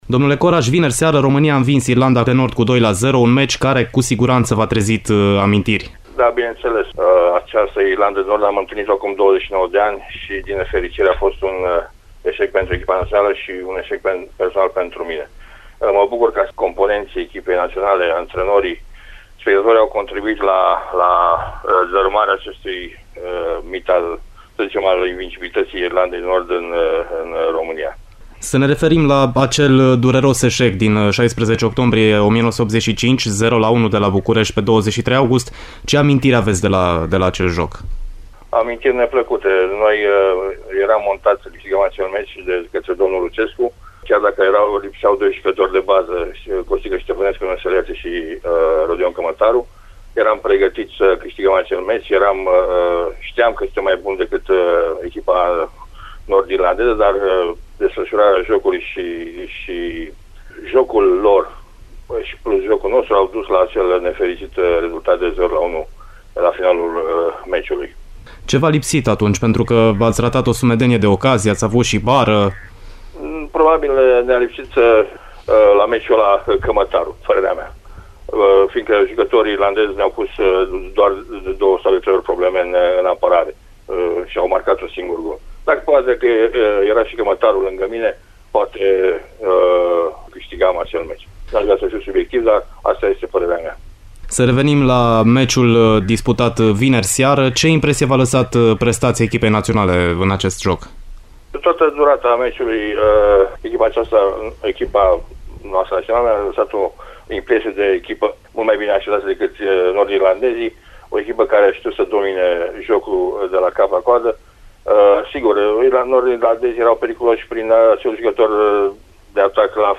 Marcel Coraş (foto, primul din dreapta de pe rândul de sus) a rememorat, la Radio Timişoara, acel joc de tristă amintire de pe stadionul „23 august”.